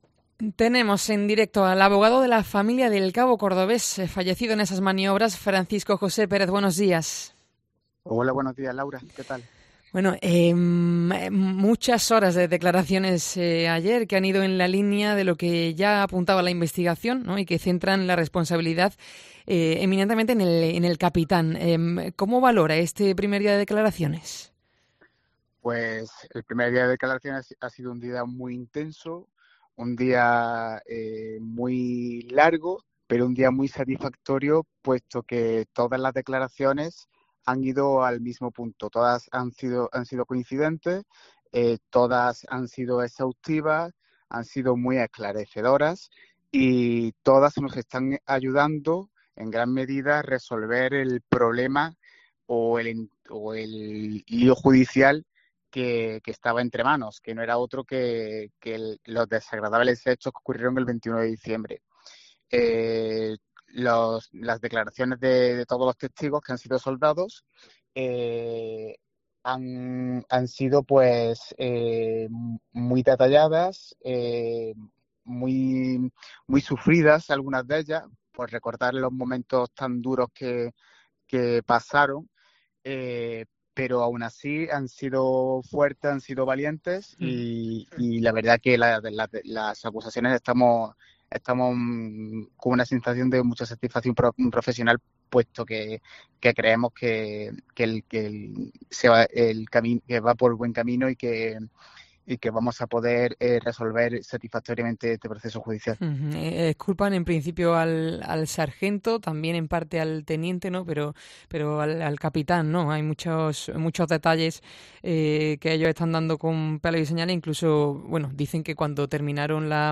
En Directo COPE CÓRDOBA